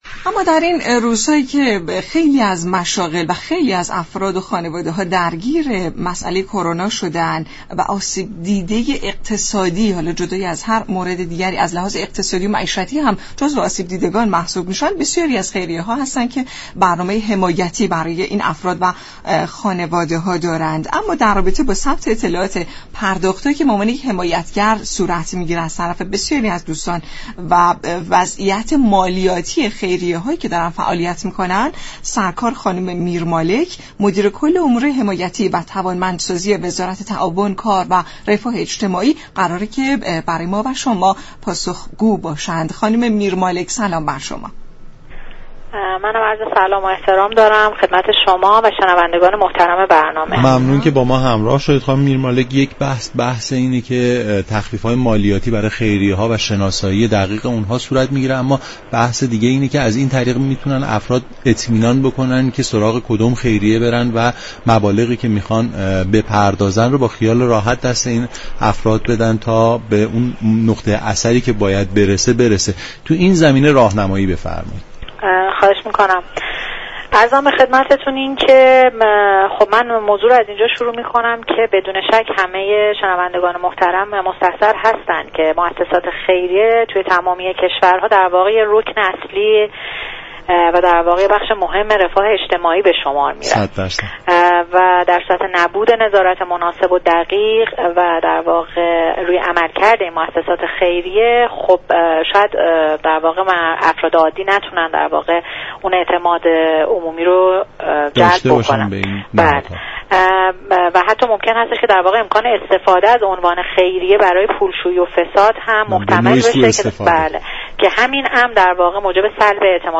مدیركل دفتر امور حمایتی و توانمند سازی وزارت تعاون، كار و رفاه اجتماعی گفت: موسسات خیریه موظفند تمامی حمایت های خود را به تفكیك شماره و كد ملی دریافت كننده حمایت، در سامانه وزارت تعاون ثبت كنند.
برنامه نمودار شنبه تا چهارشنبه هر هفته ساعت 10:20 از رادیو ایران پخش می شود.